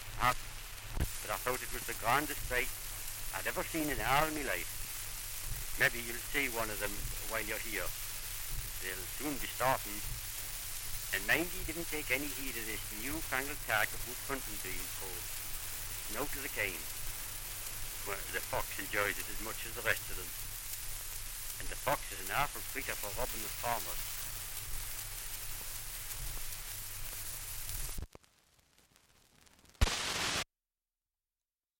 Dialect recording in Rothbury, Northumberland
78 r.p.m., cellulose nitrate on aluminium.